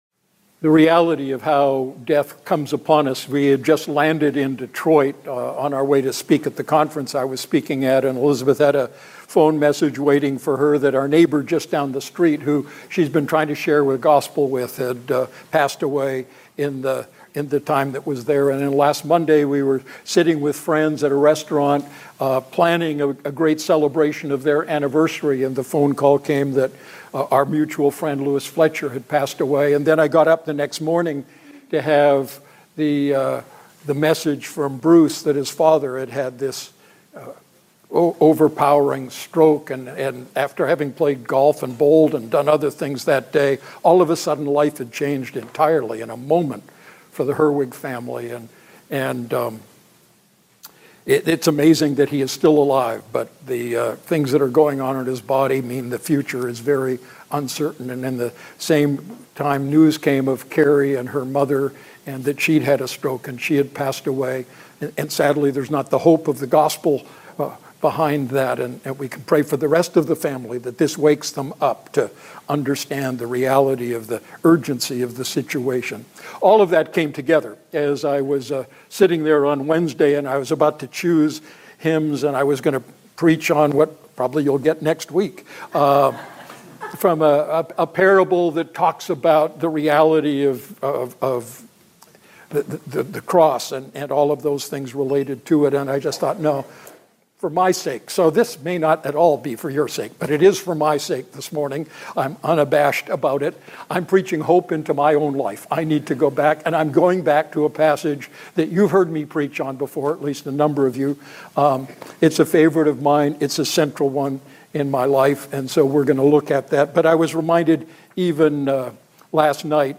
Standalone Sermon